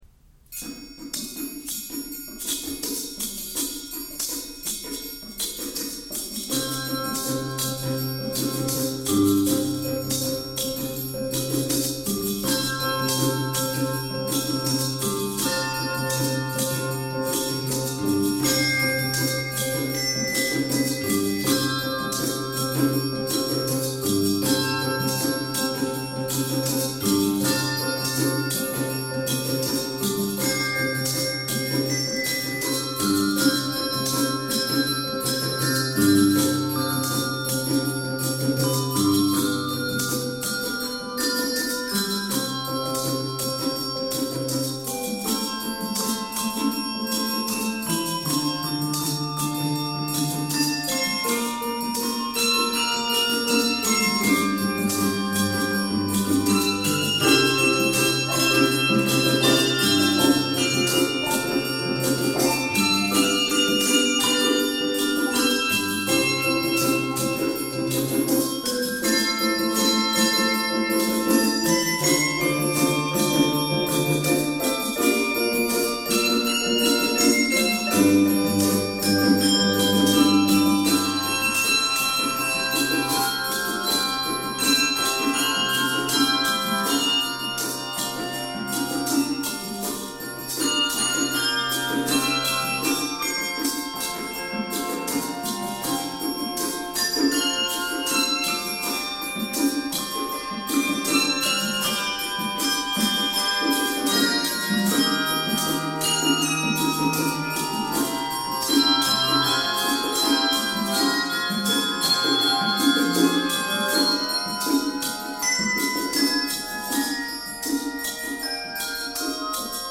features malleting and gyros
is more flowing and quiet